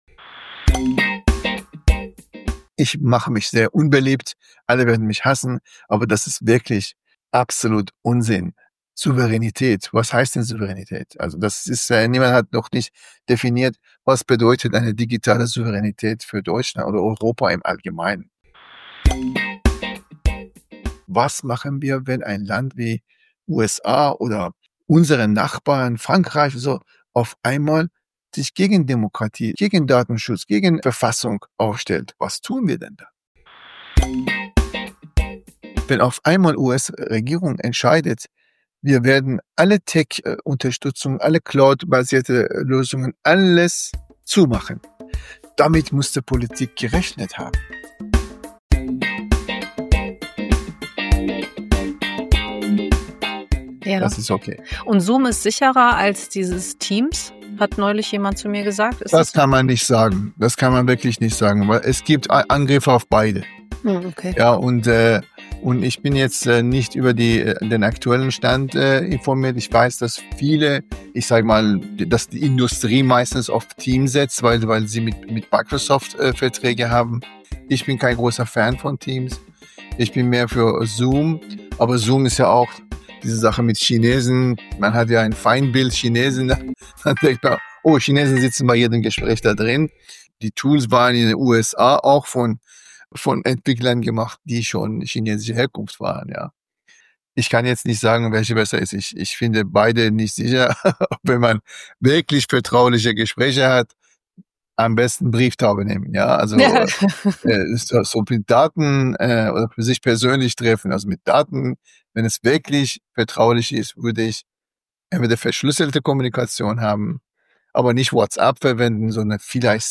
Social Media für Glaube und Kirche - das ist der yeet-Podcast: yeet-Redakteur* innen befragen Expert* innen und Influencer* innen und begeben sich auf die Suche nach den großen und kleinen Perspektiven auf die digitalen Kirchen-Räume und Welten in den Sozialen Medien.